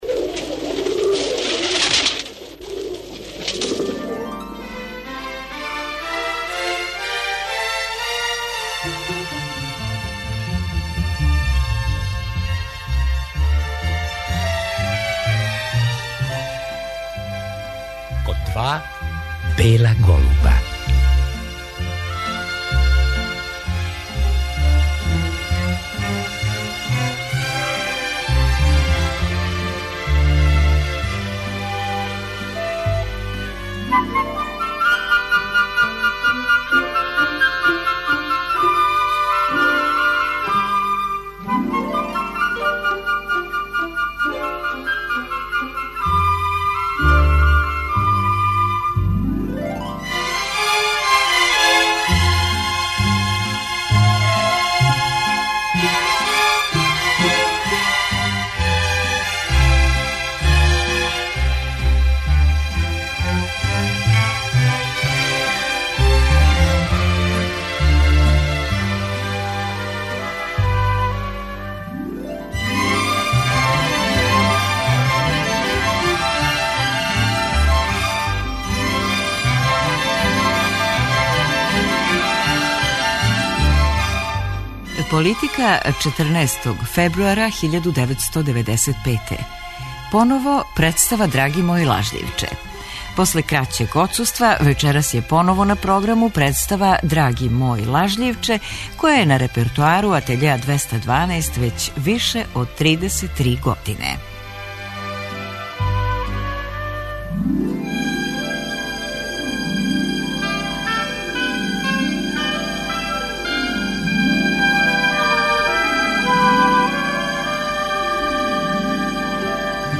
За вечерашњу емисију одабрали смо одломке из снимка представе.